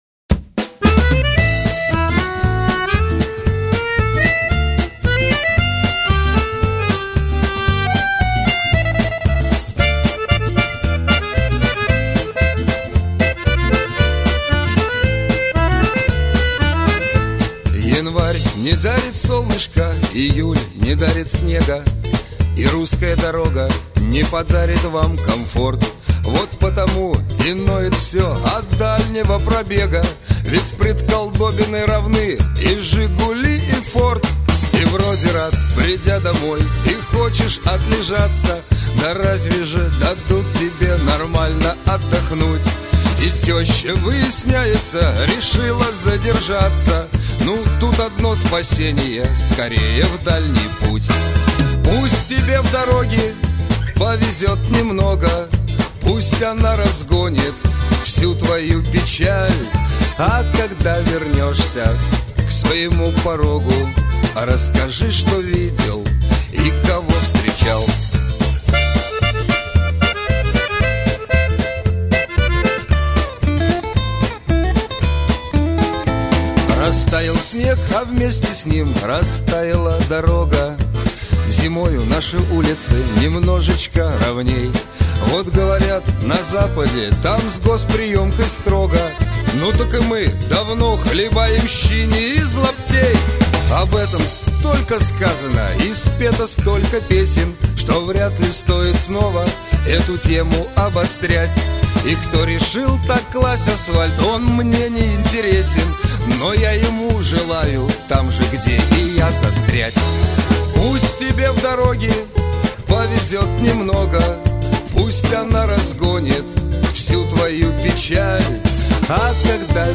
Главная » Музыка » Шансон